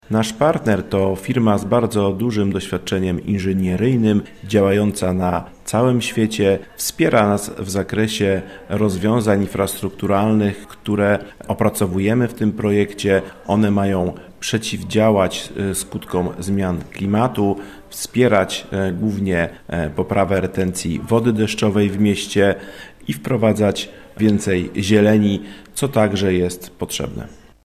– mówi Tomasz Miler, zastępca Burmistrza Gryfina.